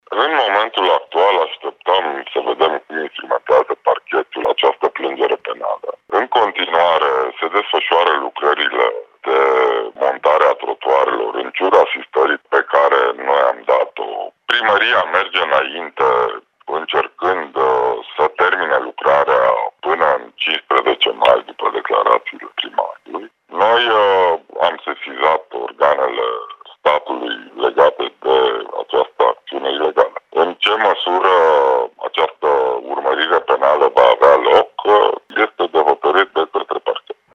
Directorul Direcției Județeană pentru Cultură Timiș, Sorin Predescu.